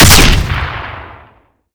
port A2 incendiary grenades